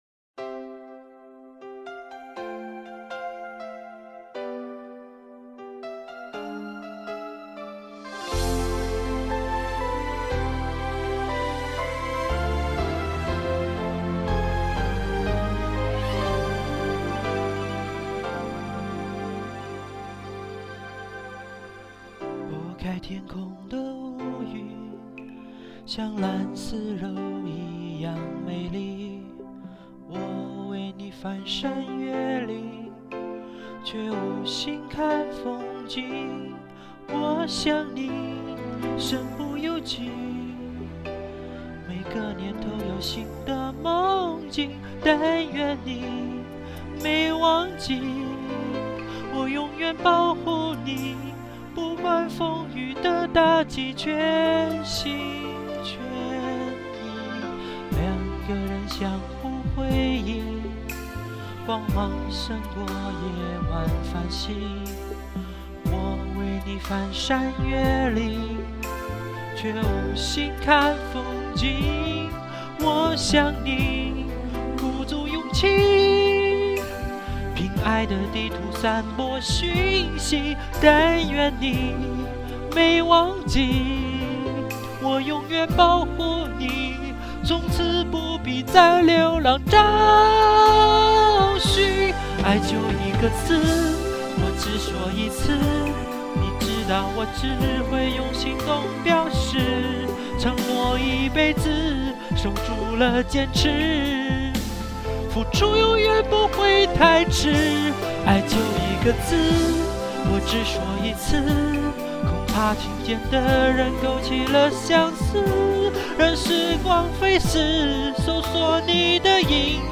虽然没唱好，不过懒得再录了，鬼哭狼嚎很有感情{:5_141:}
哈哈，有点失望吧，么办法啊，俺不小心走调严重
话说混响是不是我原来把伴奏和声音一起录，不像现在是录了声音然后陪上伴奏？